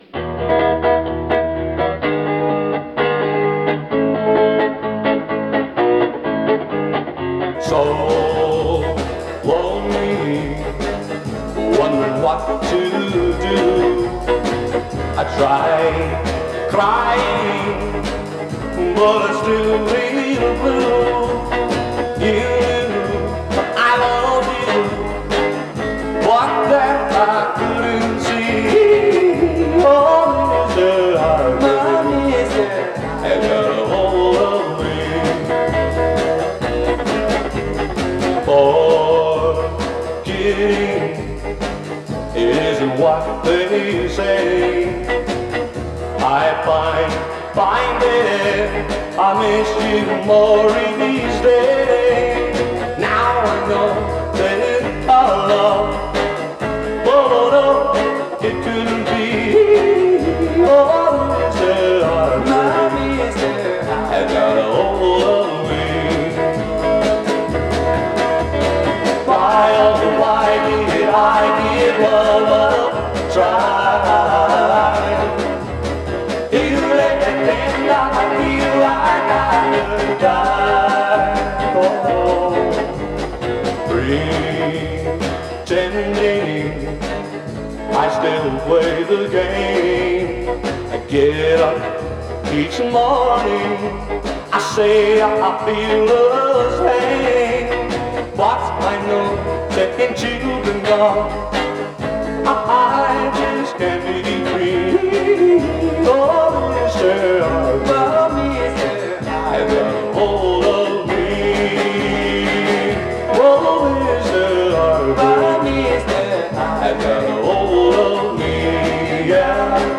Twee pop, éloge de la fadeur- 13 mars 2025